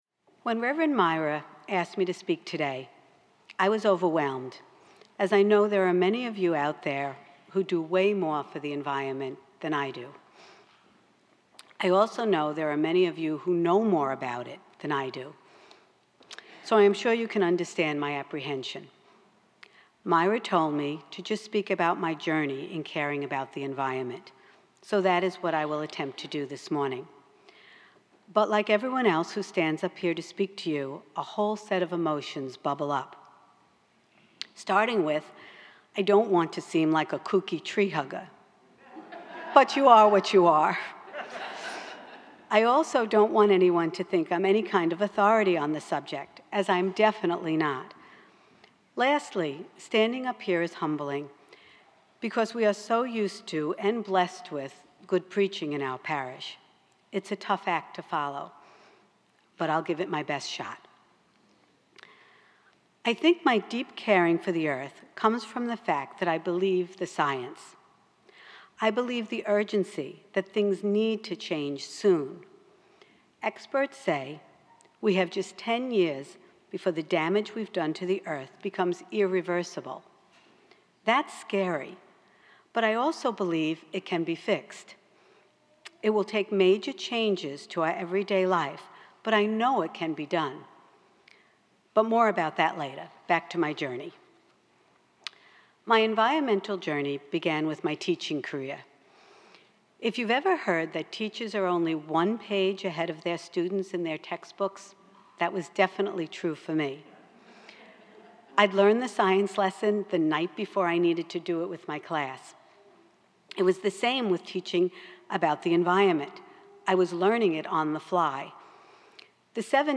Earth Day Liturgy 2021